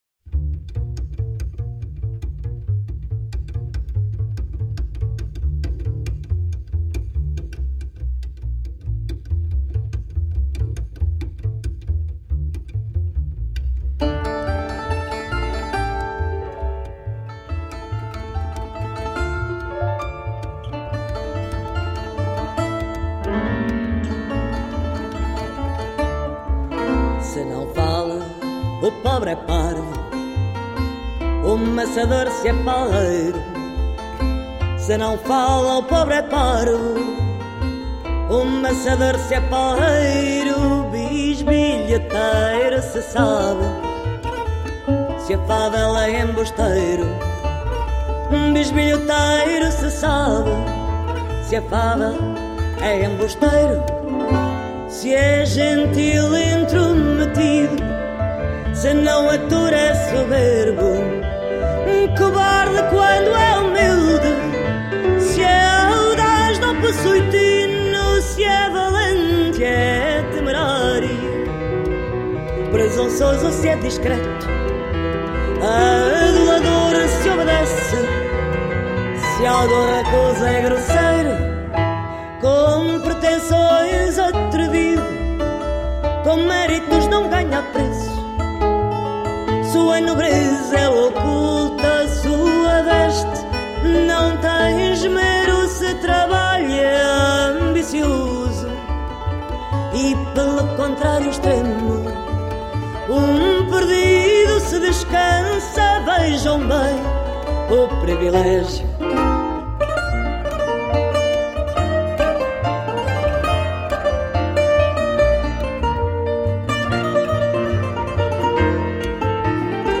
smoky, passionate voice
Tagged as: World, Latin